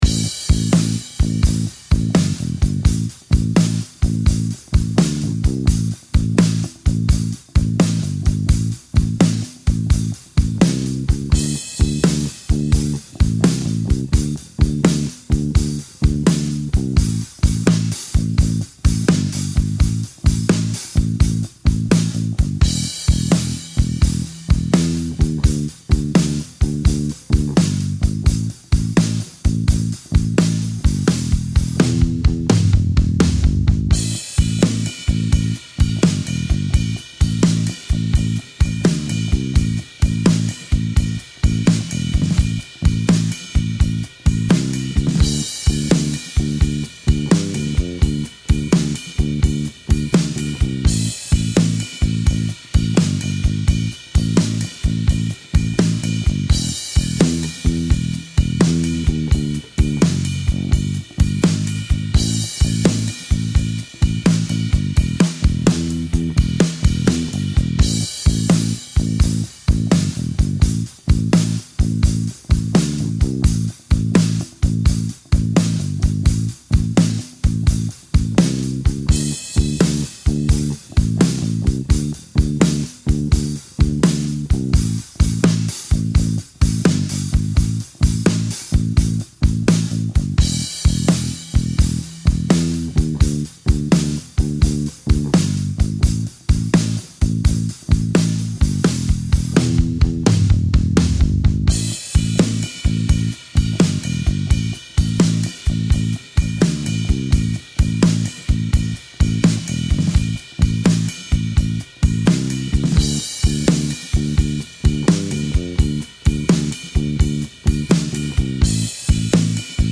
Guitar Lessons: Blues Rhythm Riffs